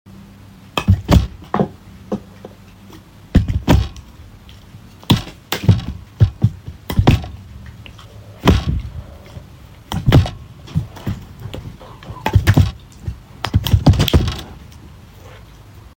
I'm lustful 🤤 Galactic explosion sound effects free download